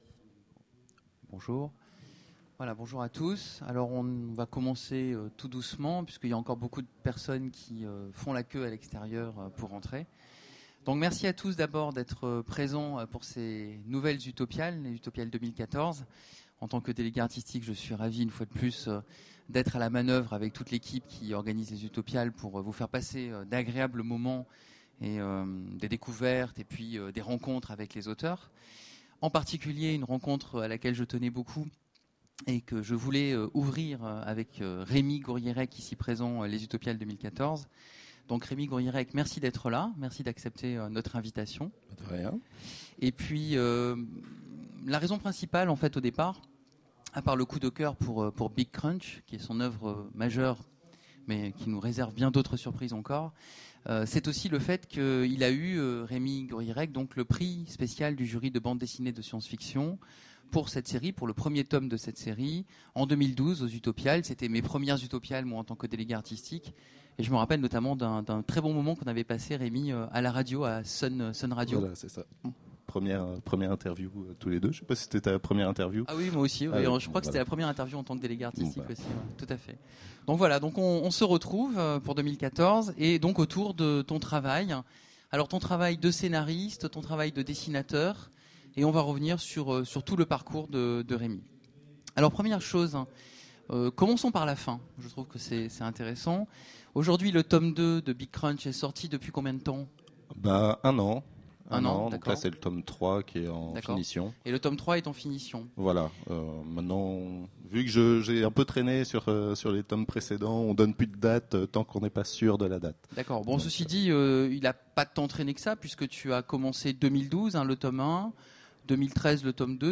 - le 31/10/2017 Partager Commenter Utopiales 2014
Mots-clés Rencontre avec un auteur Conférence Partager cet article